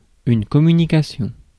Ääntäminen
France: IPA: [kɔ.my.ni.ka.sjɔ̃]